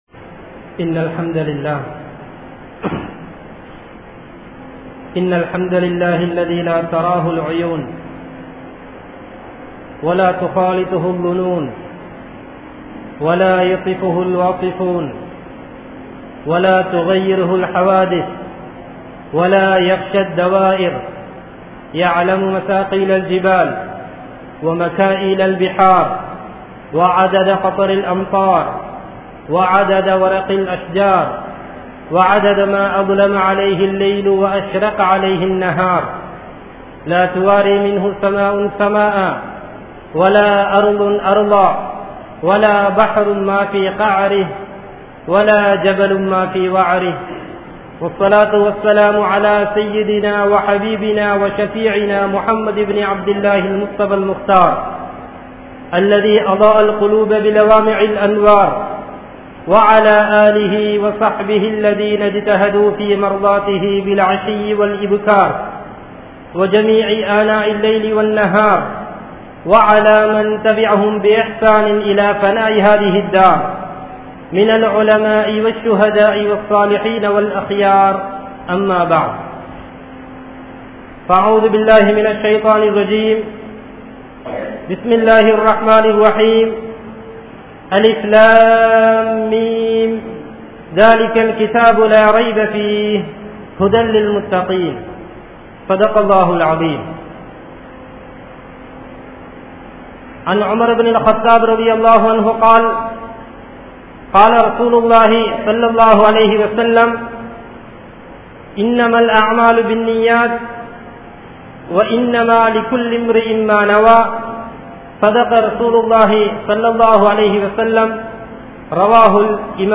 History of the Madhrasathul Baari Arabic College | Audio Bayans | All Ceylon Muslim Youth Community | Addalaichenai